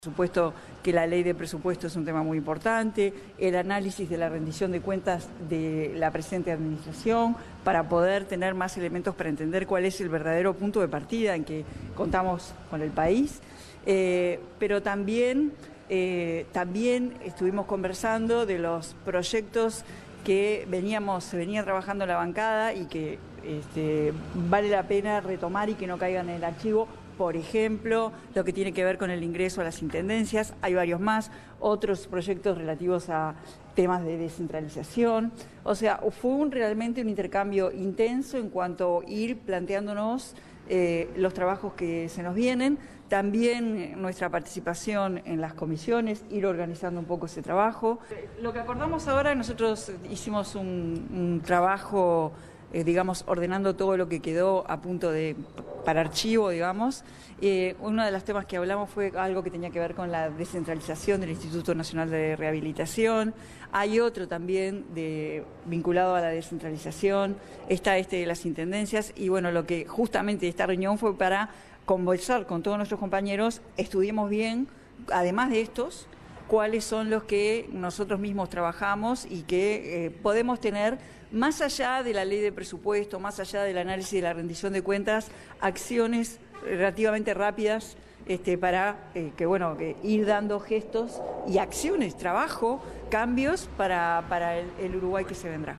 Cosse indicó en rueda de prensa, que la Ley de Presupuesto será importante, pero también el análisis de la próxima Rendición de Cuentas, con el fin de “tener más elementos para entender el punto de partida”.
Escuche a Carolina Cosse aquí: